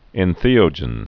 (ĕn-thēō-jən)